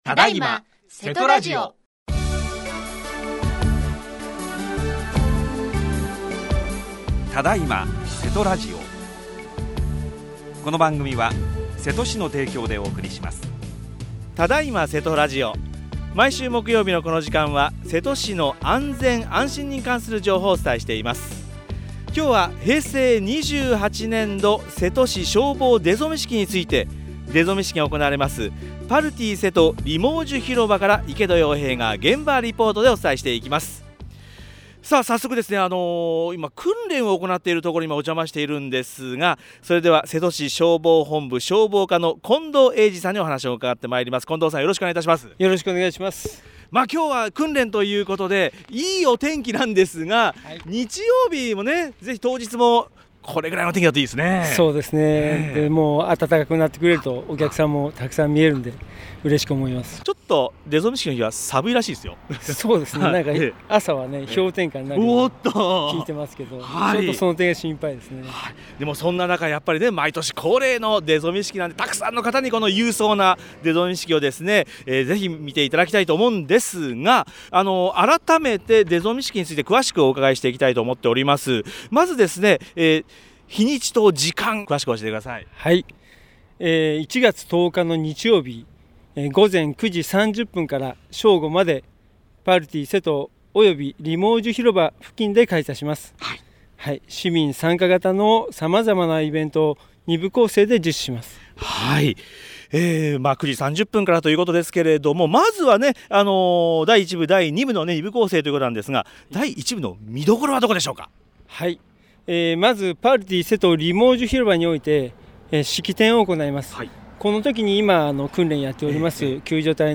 28年1月7日（木） 只今せとラジオ 毎週木曜日は「安全・安心情報」です。 今日は現場リポート。